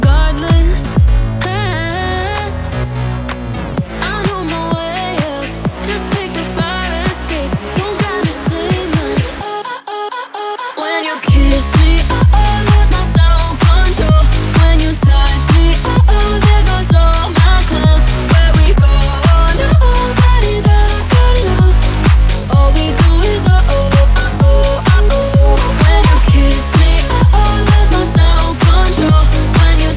Dance
Жанр: Танцевальные